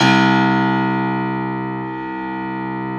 53f-pno02-C0.wav